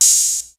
Open Hat [10].wav